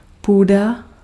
Ääntäminen
Ääntäminen Tuntematon aksentti: IPA: /puːda/ Haettu sana löytyi näillä lähdekielillä: tšekki Käännös Ääninäyte Substantiivit 1. earth US UK 2. soil 3. grounds US 4. land US 5. attic 6. garret US 7. loft Suku: f .